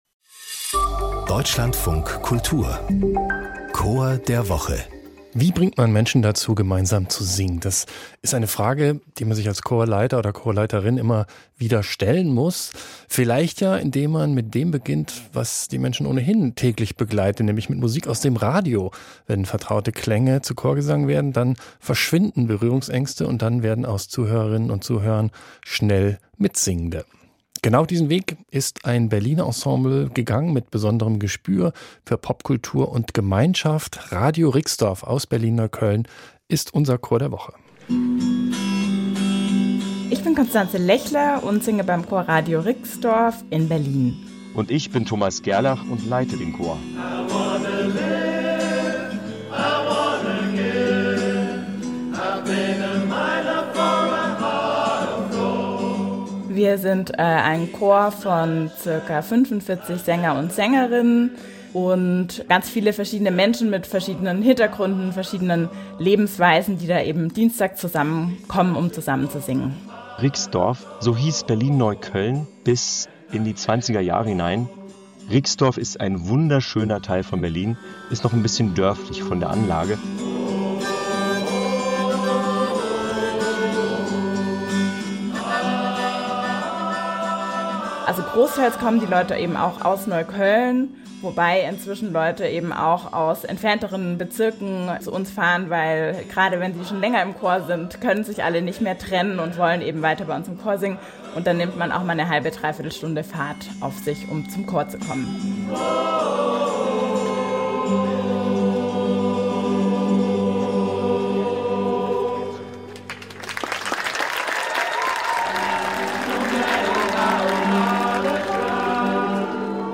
Hier stellen wir Ihnen jede Woche einen Chor vor.